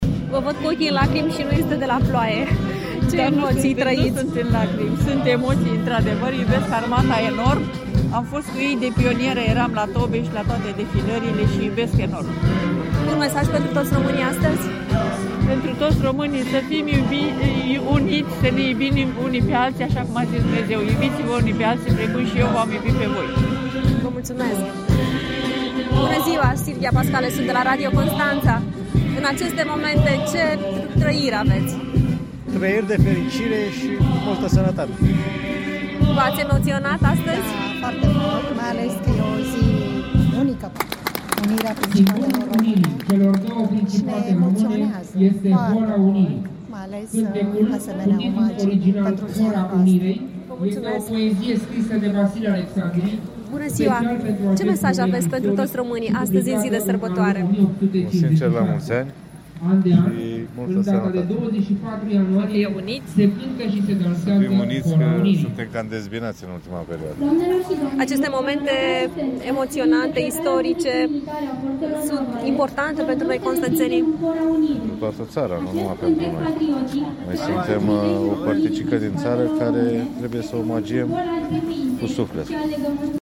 (REPORTAJ) CONSTANȚA: Ziua Unirii Principatelor Române, sărbătorită în Piața Ovidiu
Zeci de oameni, de toate vârstele, au participat la evenimentul care s-a încheiat cu Hora Unirii.
VOX.mp3